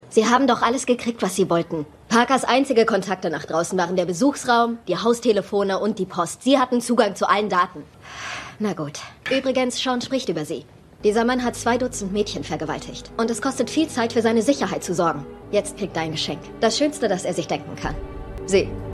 CPD_10x12_Gefaengnisdirektorin.mp3